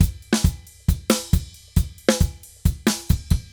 Drums_Merengue 136-4.wav